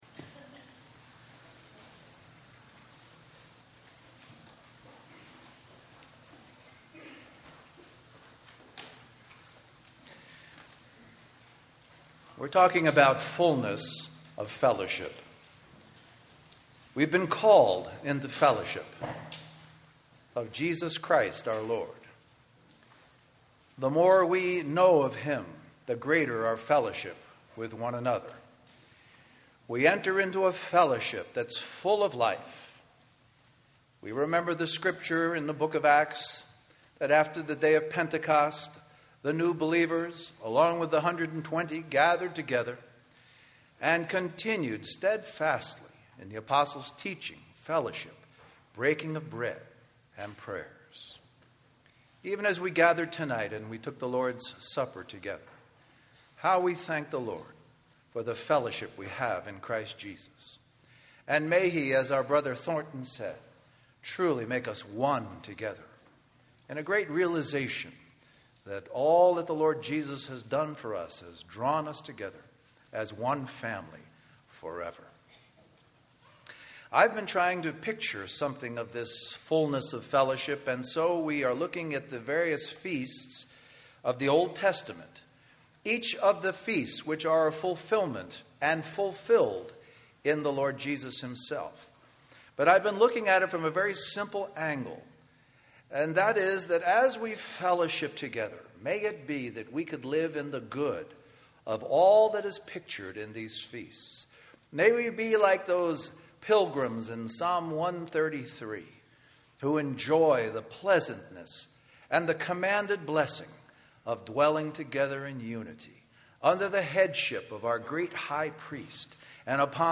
A collection of Christ focused messages published by the Christian Testimony Ministry in Richmond, VA.
Western Christian Conference